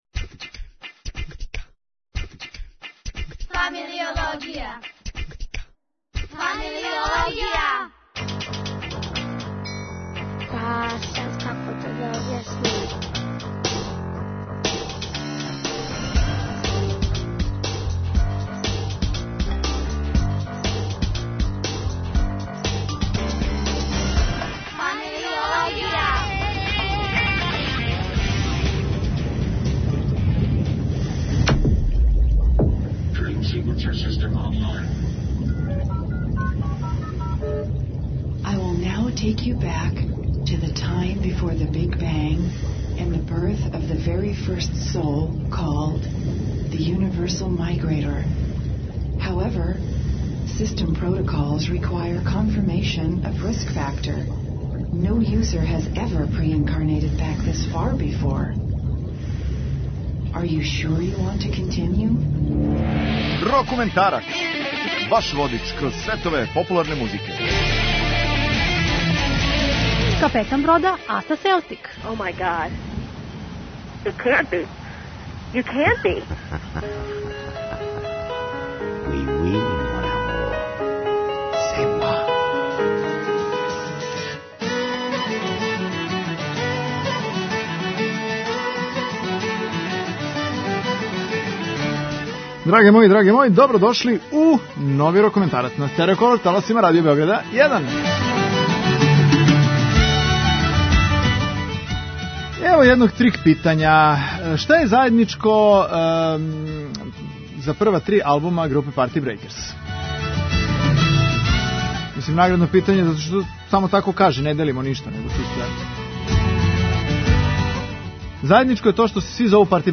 Чист рокенрол!